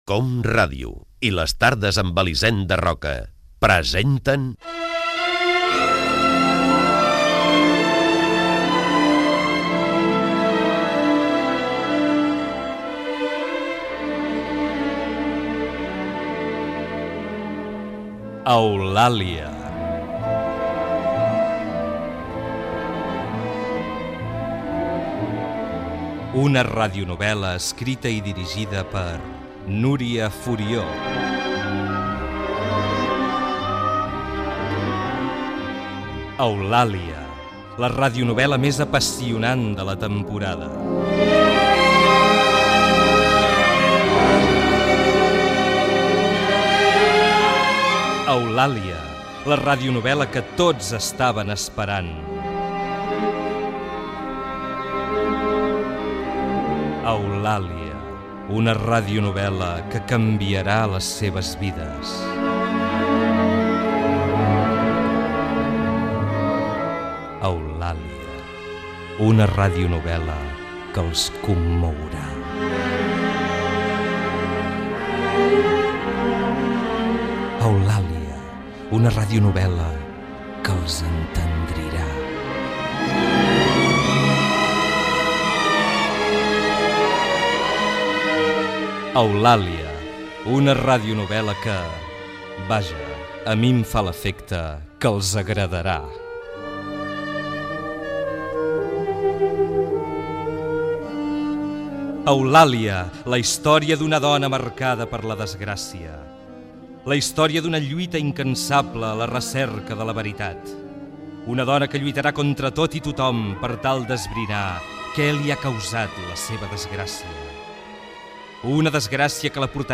Fragment del primer capítol de la radionovel·la "Eulàlia" escrita per Núria Furió. Interpretada per Rosa Maria Sardà
Ficció
FM